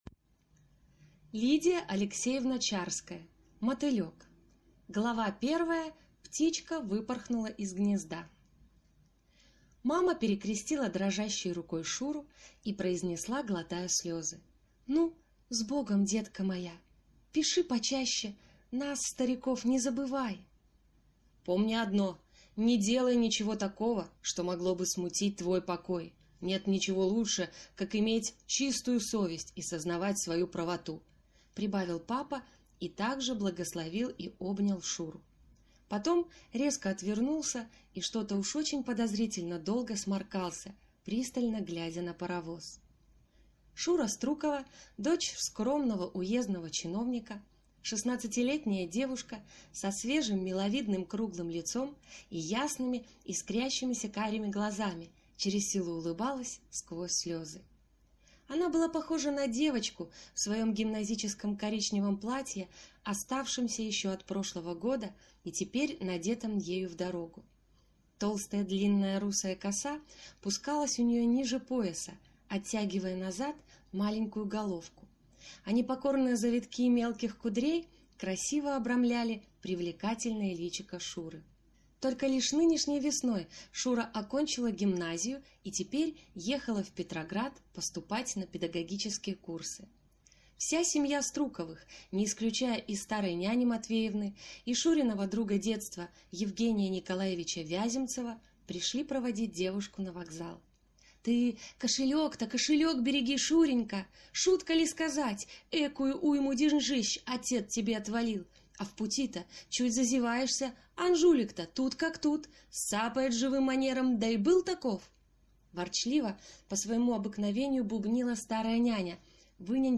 Аудиокнига Мотылек | Библиотека аудиокниг